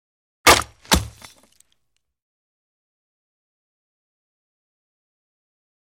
Звук брони проткнули топором